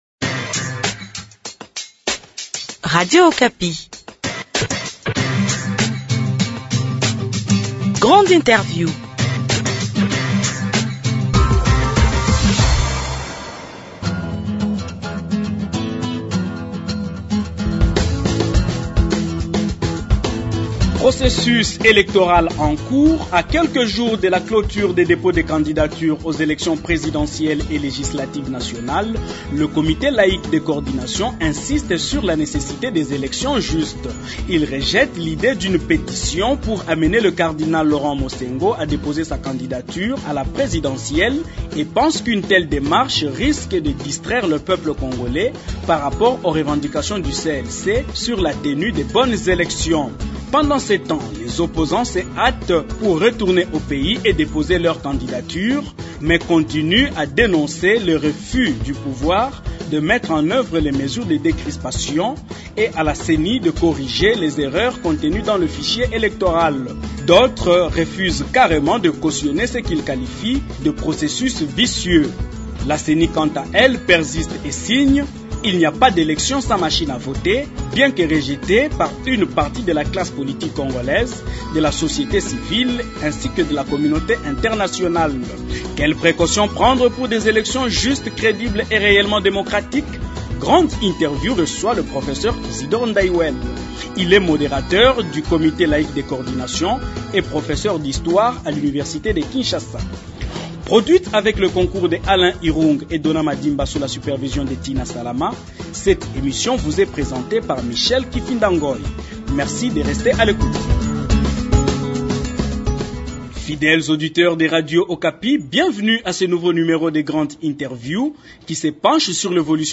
L’invité de Grande Interview s’appelle Isidore Ndaywell.